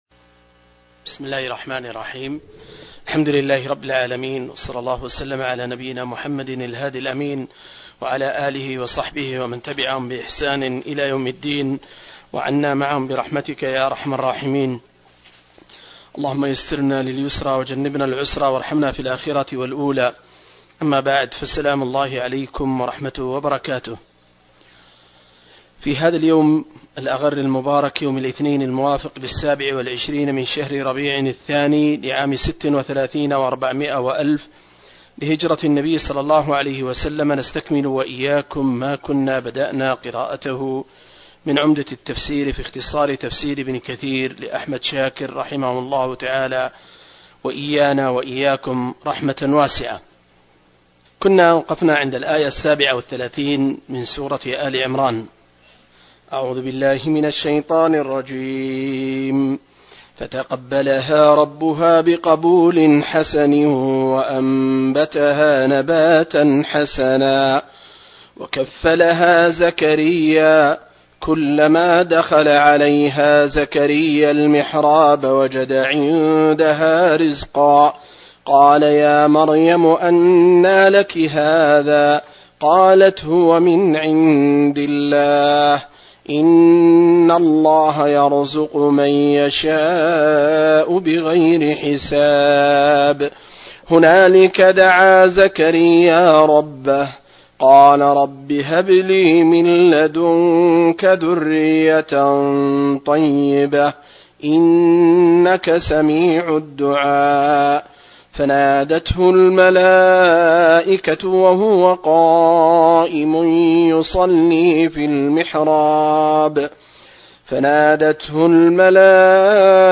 065- عمدة التفسير عن الحافظ ابن كثير رحمه الله للعلامة أحمد شاكر رحمه الله – قراءة وتعليق –